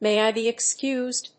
アクセントMay [Can] I be excúsed?